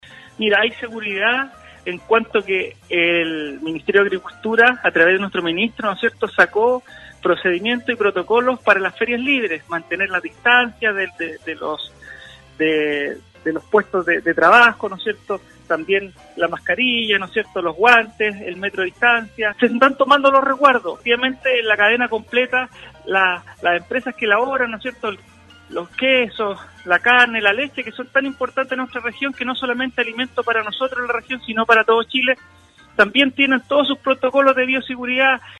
El Seremi de Agricultura Juan Vicente Barrientos, en conversación con Radio SAGO, mostró su confianza en el sector productivo para que la cadena alimentaria se mantenga funcionando sin interrupciones en esta crisis por COVID-19, luego que se están respetando todas las medidas de bioseguridad.